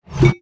Minecraft Version Minecraft Version 25w18a Latest Release | Latest Snapshot 25w18a / assets / minecraft / sounds / item / bottle / fill_dragonbreath2.ogg Compare With Compare With Latest Release | Latest Snapshot
fill_dragonbreath2.ogg